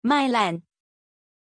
Pronunciation of Mylann
pronunciation-mylann-zh.mp3